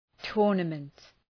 Προφορά
{‘tʋrnəmənt}